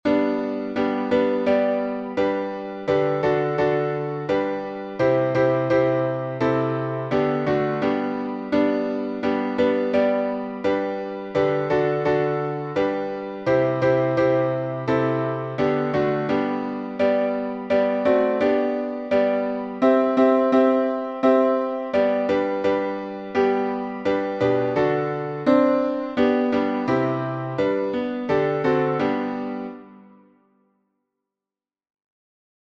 #5003: The Solid Rock — G major | Mobile Hymns